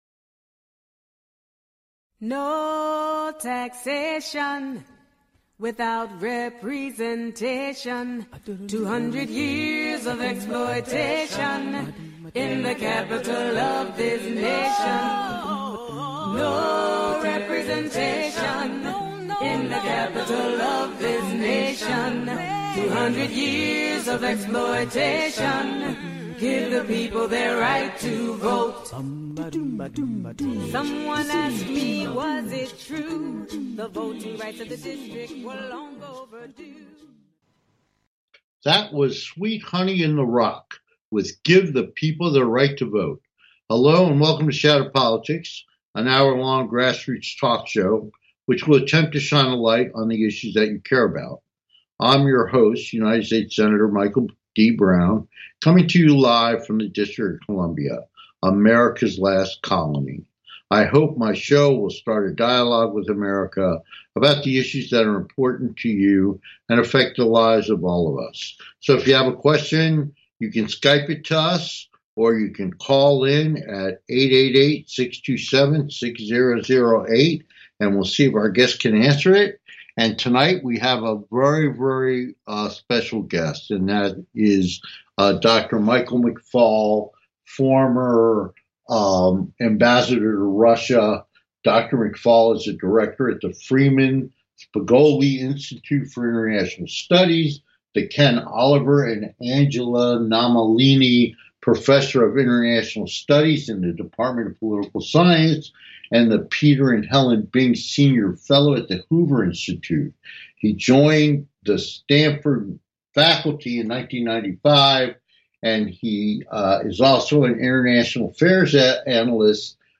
March to Moscow… Our guest, US Ambassador to Russia - Michael McFaul !